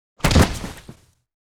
SFX怪物跌倒音效下载
SFX音效